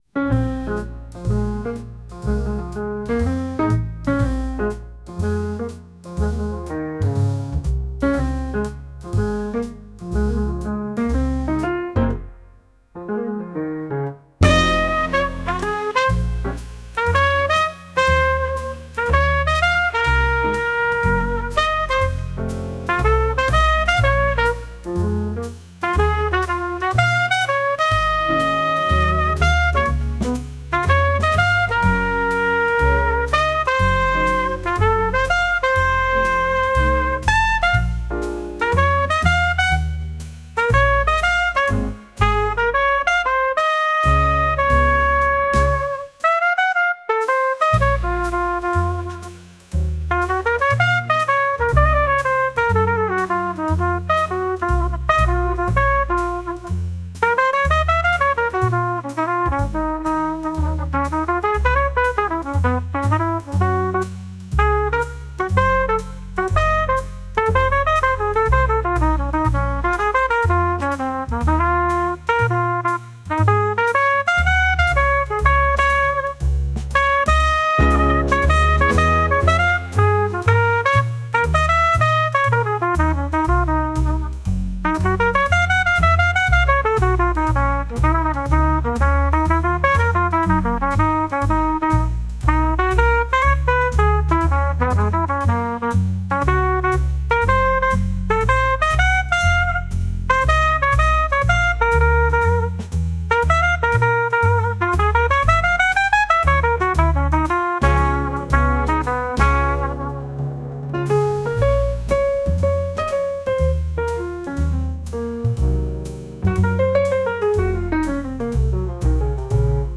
ジャンルJAZZ
楽曲イメージBar, Chill, Lo-Fi, ゆったり, カフェ, ムーディー, , 大人